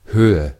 Käännös Konteksti Ääninäyte Substantiivit 1.